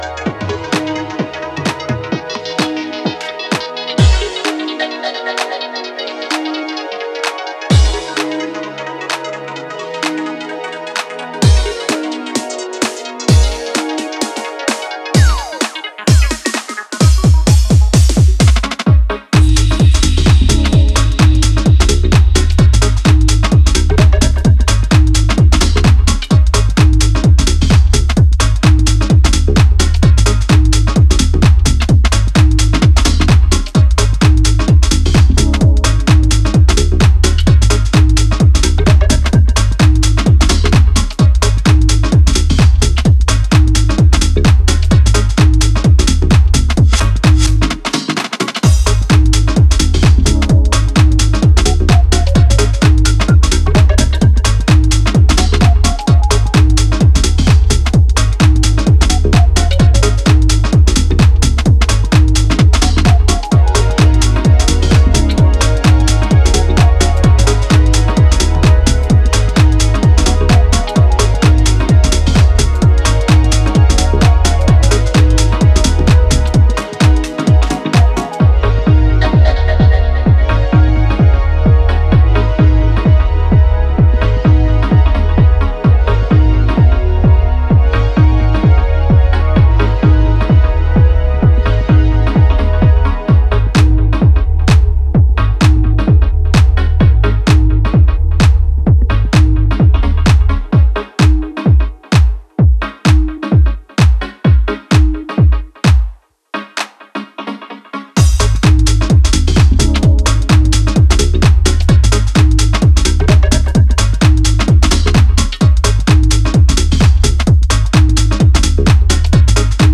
a classy deep and minimal house affair